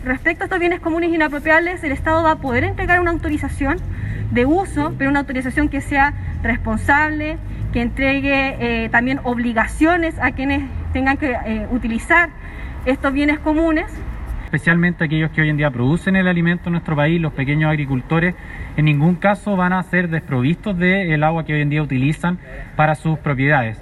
Así lo señalaron los co-coordinadores de la instancia, Camila Zárate y Juan José Martin.